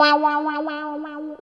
64 GUIT 2 -L.wav